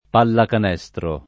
vai all'elenco alfabetico delle voci ingrandisci il carattere 100% rimpicciolisci il carattere stampa invia tramite posta elettronica codividi su Facebook pallacanestro [ pallakan $S tro ] (raro palla a canestro [ p # lla a kkan $S tro ]) s. f.